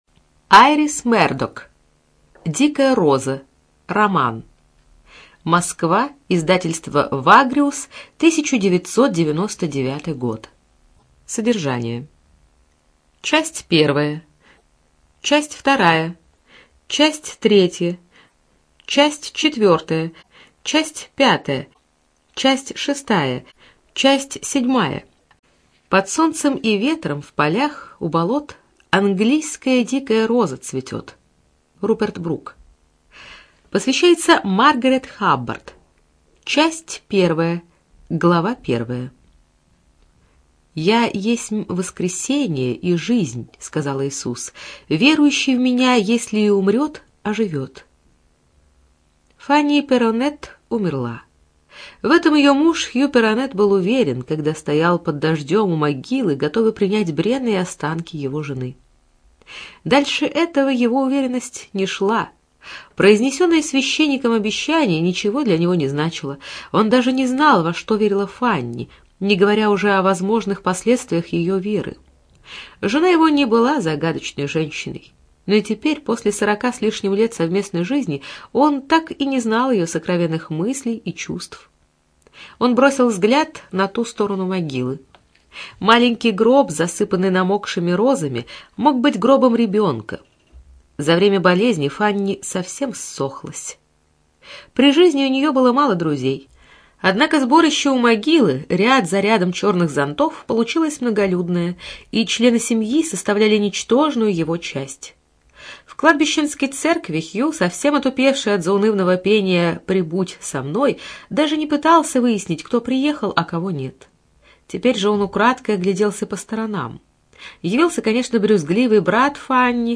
ЖанрСовременная проза
Студия звукозаписиКемеровская областная специальная библиотека для незрячих и слабовидящих